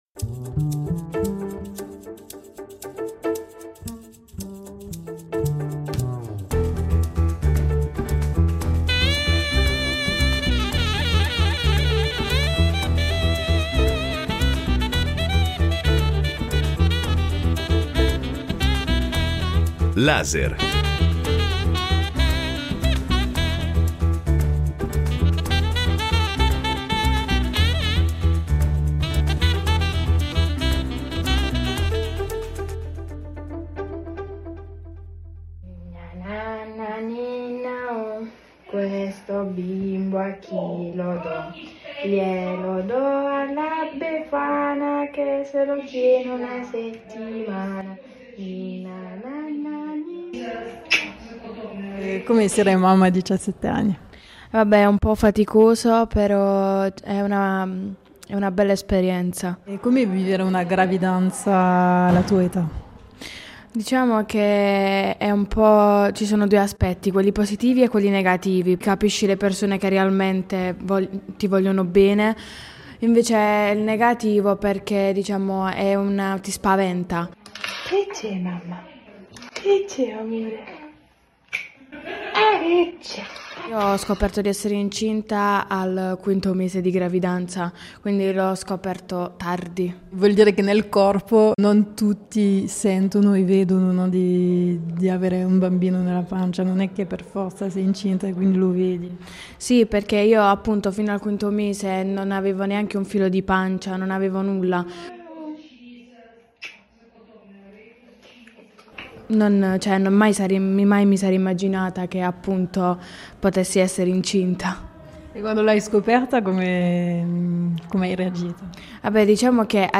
Questo documentario audio esplora invece la sua dimensione invisibile; raccoglie storie di donne che vivono la maternità in contesti culturali diversi da quelli d’origine.
La gravidanza emerge come territorio di confine, tra consapevolezza e riconoscimento. Attraverso testimonianze intime, paesaggi sonori e contributi professionali, prende forma un racconto corale.